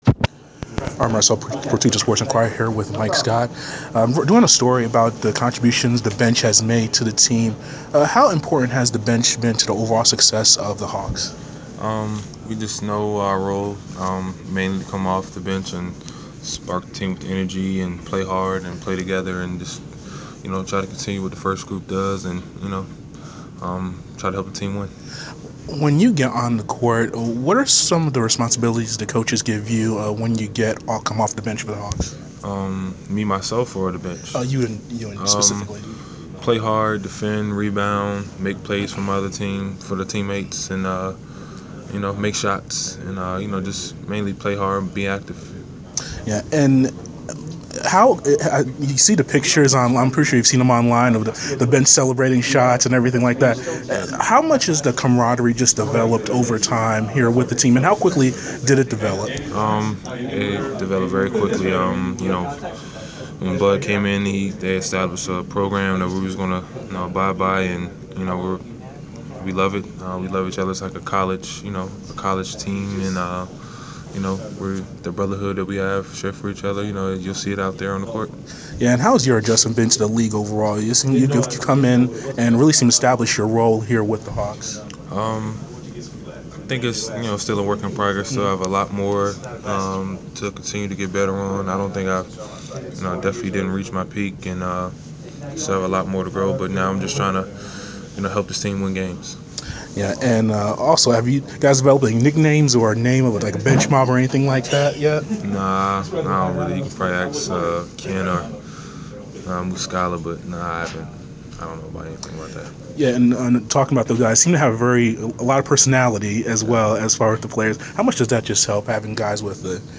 Inside the Inquirer: Pregame interview with Atlanta Hawks’ Mike Scott (1/11/15)
We caught up with Mike Scott of the Atlanta Hawks before his team’s home contest against the Washington Wizards on Jan. 11. Topics included the play of the bench and teammates supporting each other.